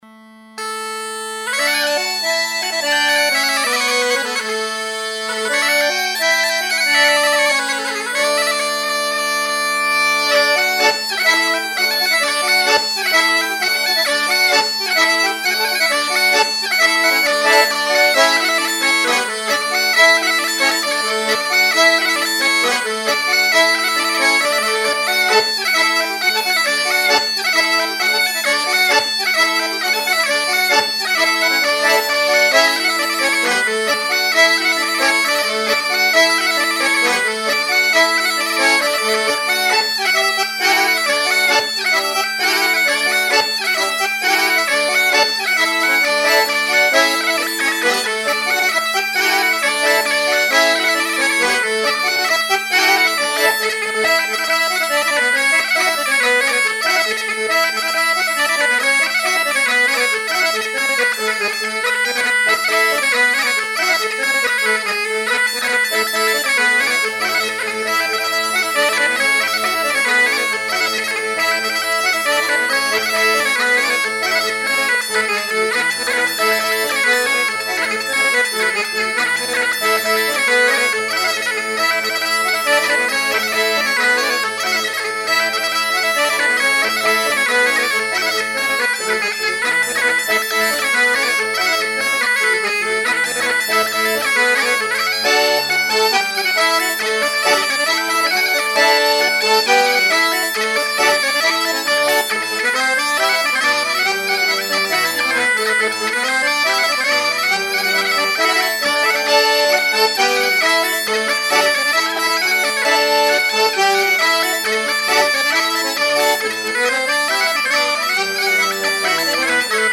Airs de maraîchine, ou branle joués à l'accordéon diatonique et à la veuze, cornemuse dont l'aire de jeu était répartie de part et d'autre de l'estuaire de la Loire
danse : branle : courante, maraîchine